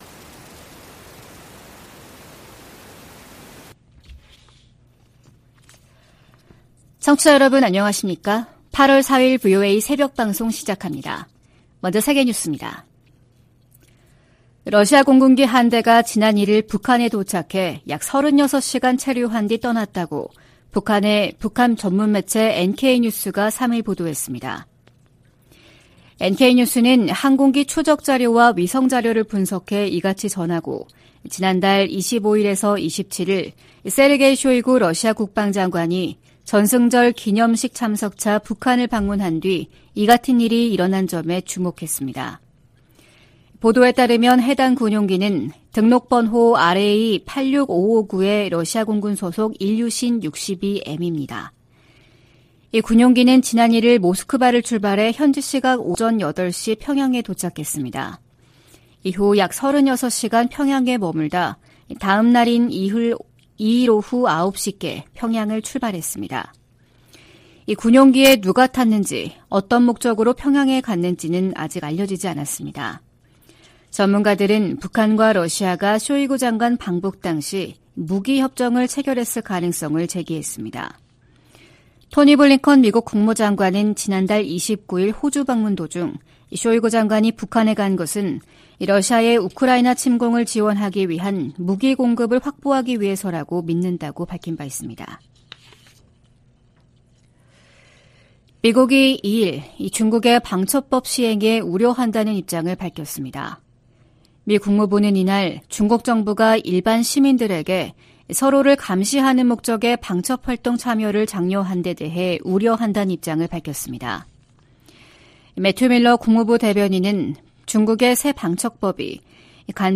VOA 한국어 '출발 뉴스 쇼', 2023년 8월 4일 방송입니다. 북한이 무단 월북 미군 병사 사건과 관련해 유엔군사령부에 전화를 걸어왔지만 실질적인 진전은 아니라고 국무부가 밝혔습니다. 핵확산금지조약(NPT) 당사국들이 유일하게 일방적으로 조약을 탈퇴한 북한을 비판했습니다. 김영호 한국 통일부 장관이 현 정부에서 종전선언을 추진하지 않을 것이라고 밝혔습니다.